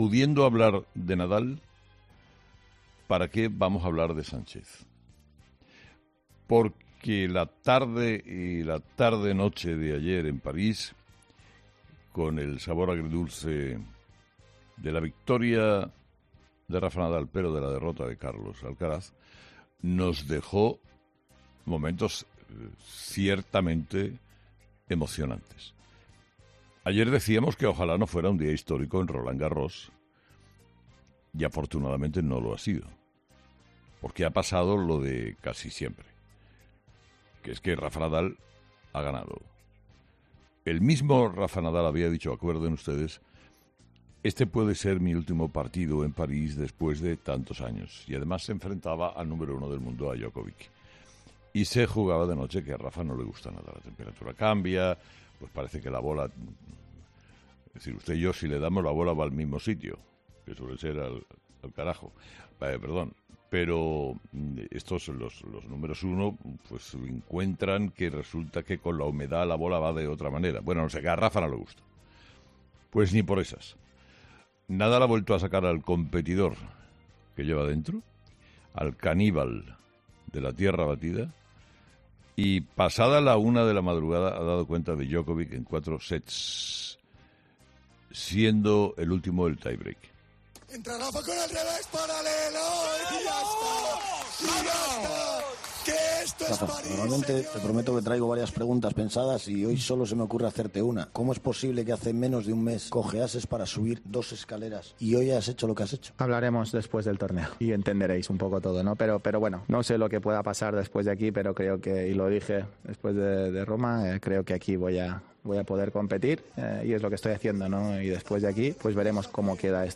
Carlos Herrera, director y presentador de 'Herrera en COPE', analizó la victoria de Rafa Nadal ante Djokovic sobre la tierra batida de la capital francesa